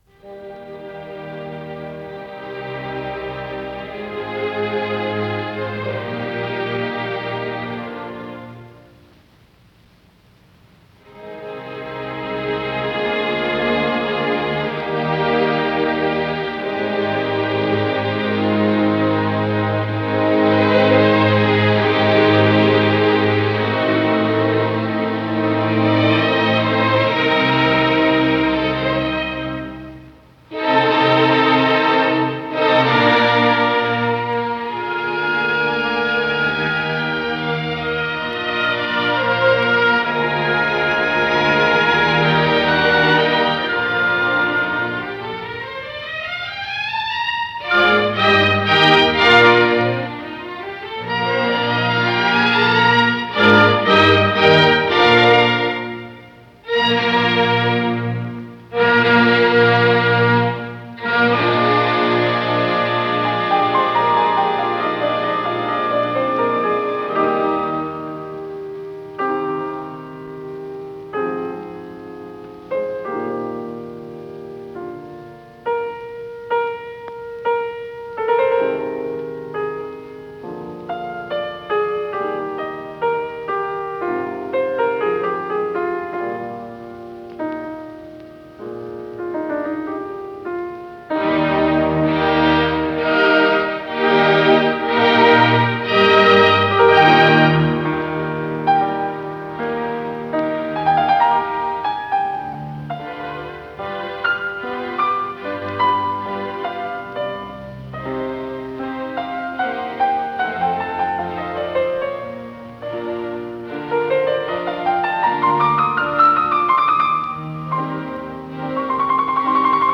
ПодзаголовокСочинение 19, си бемоль мажор
ИсполнителиУильям Каппель - фортепиано
Дирижёр - Владимир Гольшман
ВариантДубль моно